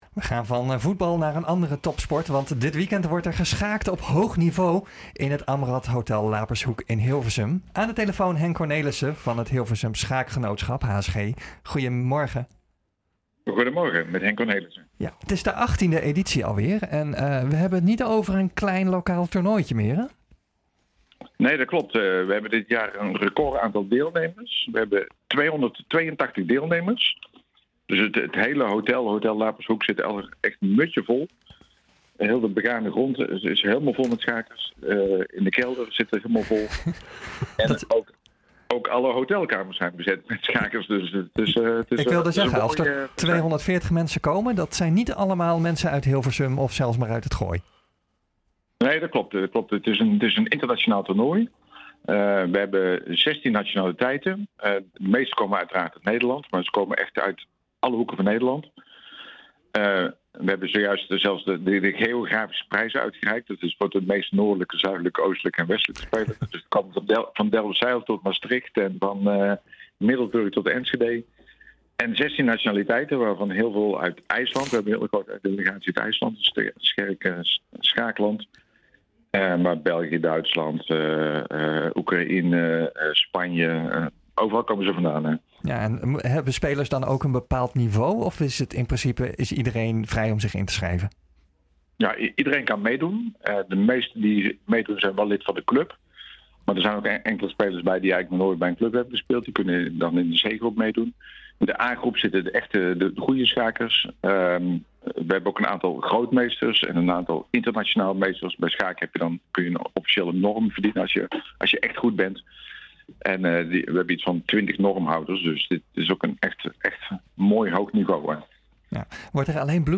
Dit weekend wordt er geschaakt op hoog niveau in het Amrâth hotel Lapershoek in Hilversum. Aan de telefoon
Dit fragment werd uitgezonden in de NH Gooi Zaterdag.